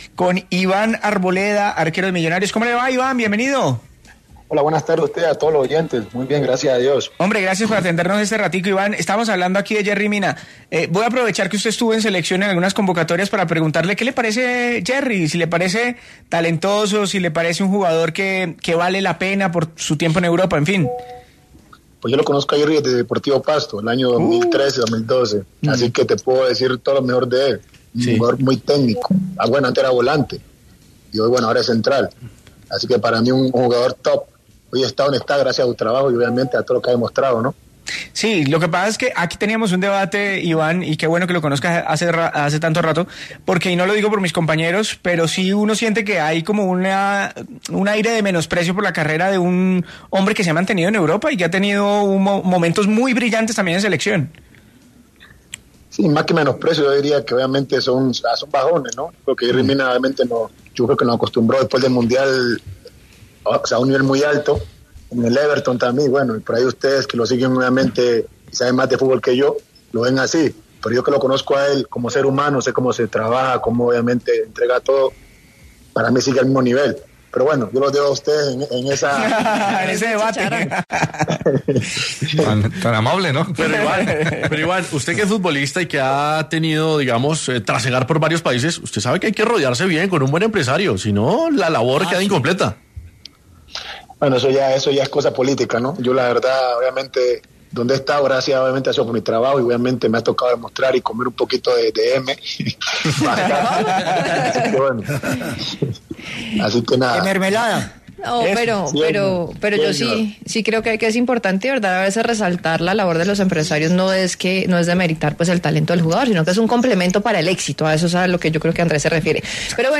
Este jueves 22 de mayo, Iván Arboleda, arquero con pasado en Rayo Vallecano, estuvo en diálogo con el Vbar de Caracol Radio donde supo dar su opinión sobre Yerry Mina, además, se manifestó cómo es ser suplente en un equipo como Millonarios y finalmente además dijo contra quien le gustaría jugar una posible final de la Liga Colombiana.